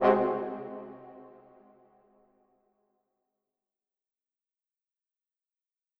Category: Percussion Hits
Brass-Mid-Hit-2.wav